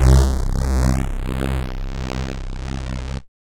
synth03.wav